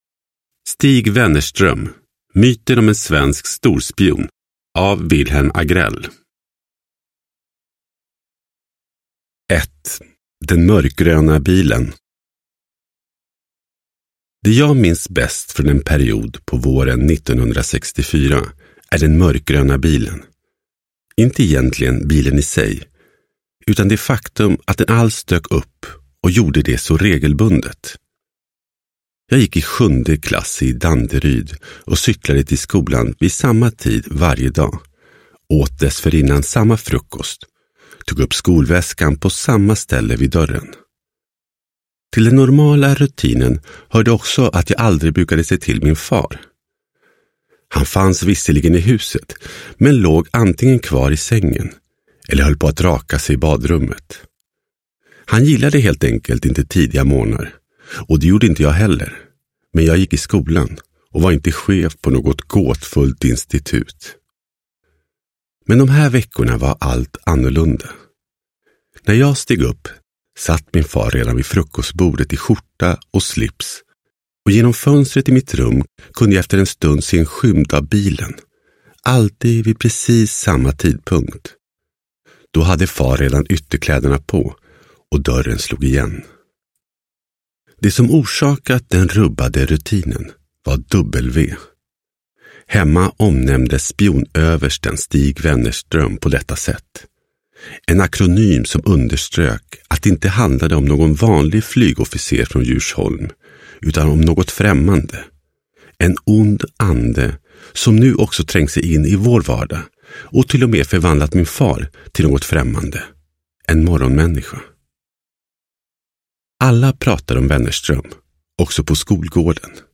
Stig Wennerström – Myten om en svensk storspion – Ljudbok – Laddas ner